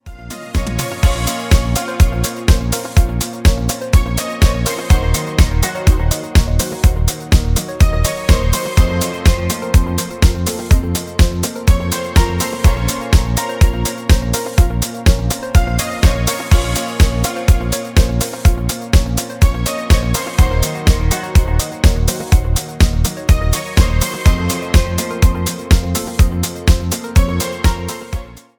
без слов
deep house , спокойные , мелодичные